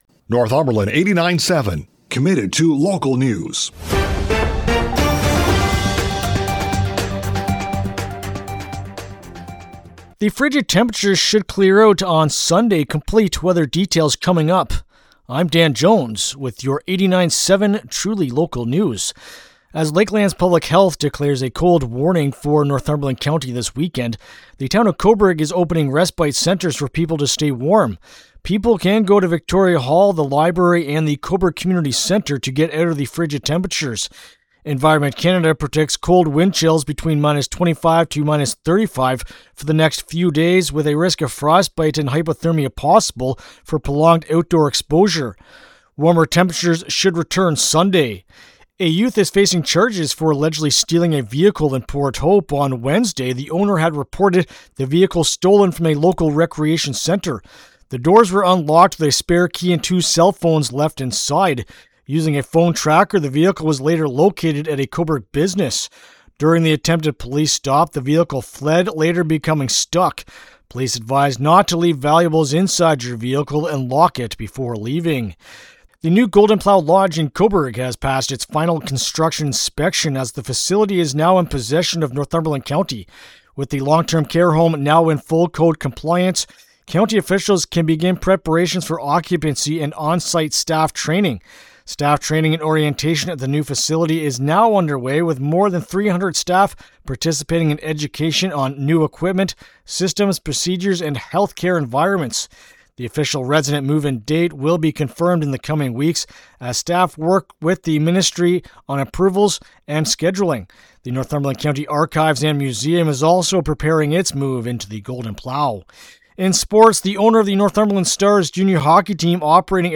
CFWN-Cobourg-On.-Friday-Jan.-23-Afternoon-News.mp3